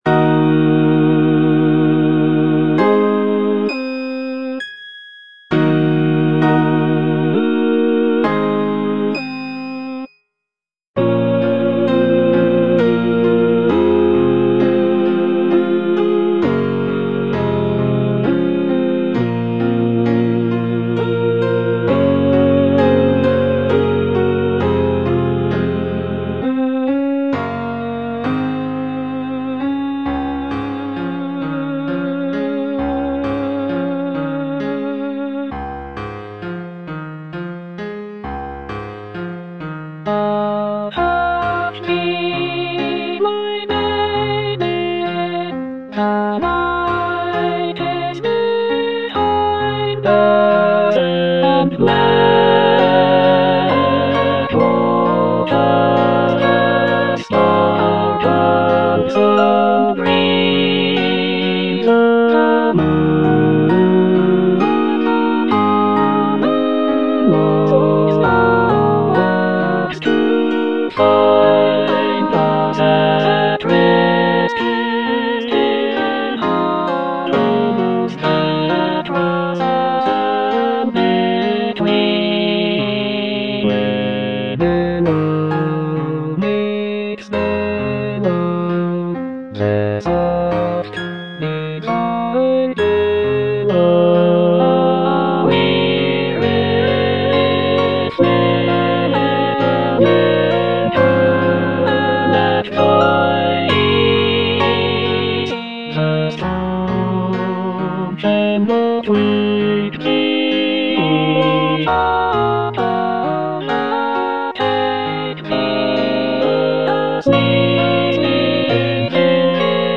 All voices
choral work